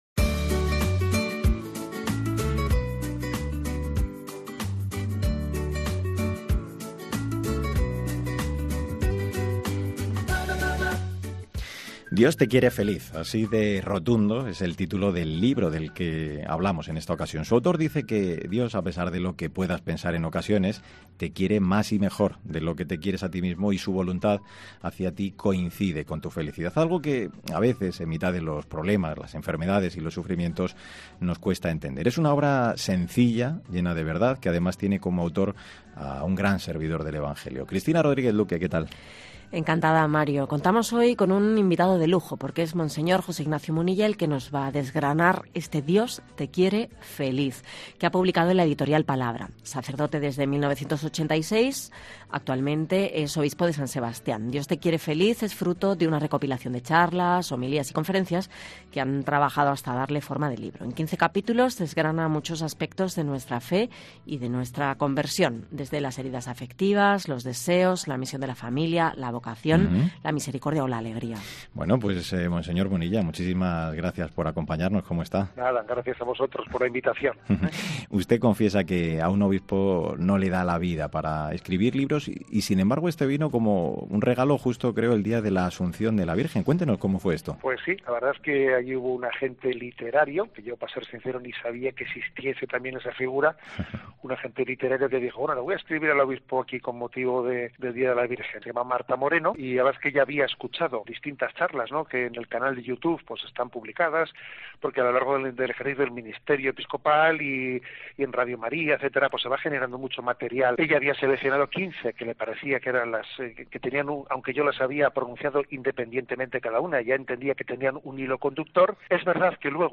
AUDIO: Entrevista al obispo de San Sebastián, José Ignacio Munilla, con motivo de su nuevo libro, "Dios te quiere feliz" editado por Palabra.